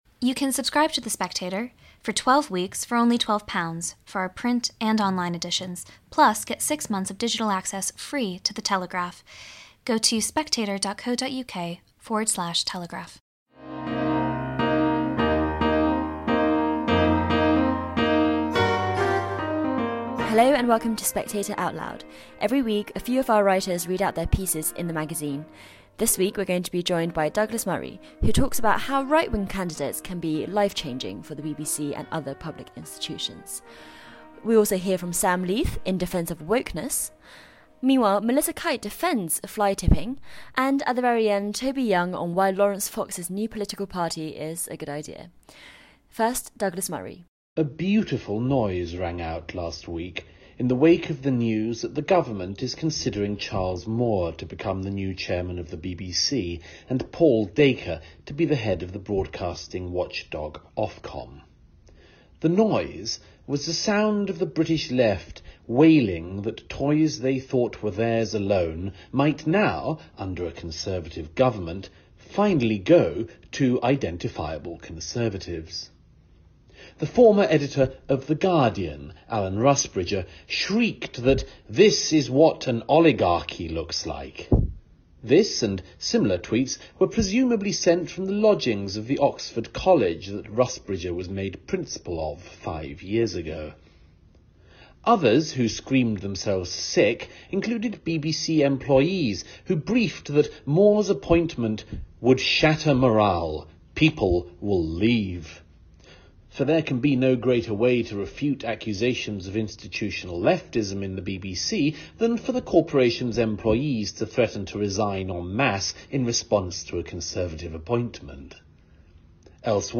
News Commentary, News, Daily News, Society & Culture
Every week, a few of our writers read out their pieces in the magazine.